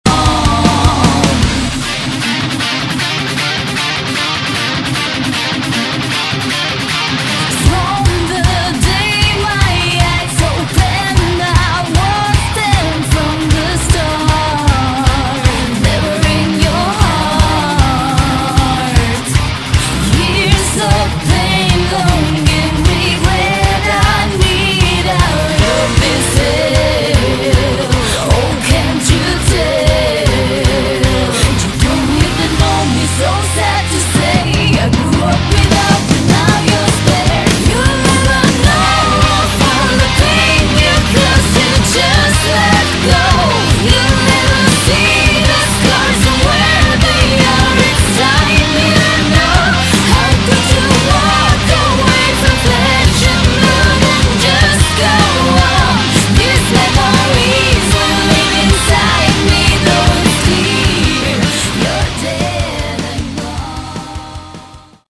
Category: Melodic Metal
guitars, bass, additional vocals
lead vocals
drums